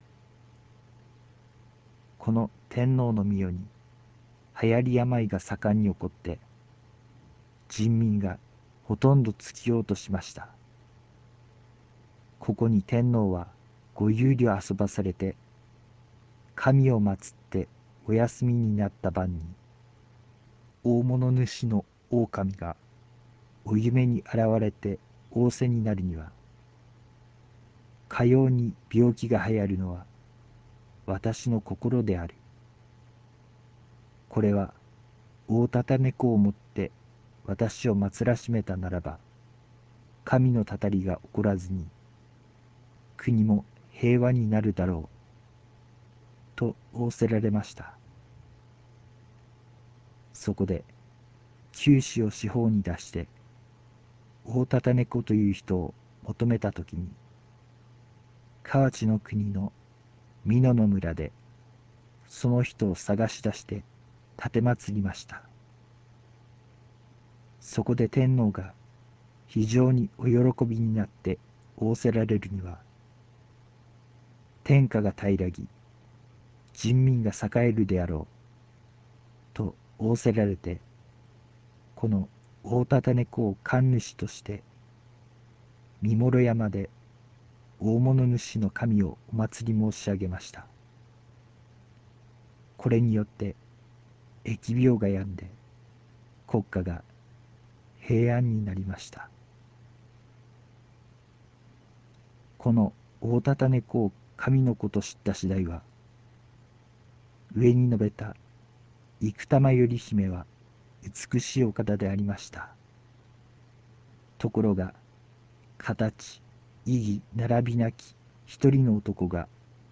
（朗読：RealMedia 形式　520KB、4'12''）